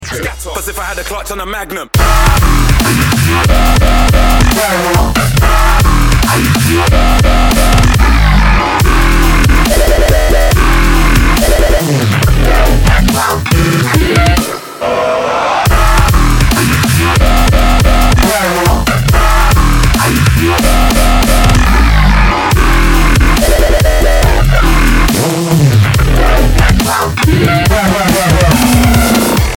Список файлов рубрики ~* Dubstep *~